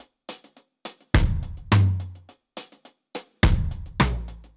Index of /musicradar/dub-drums-samples/105bpm
Db_DrumsB_Dry_105-02.wav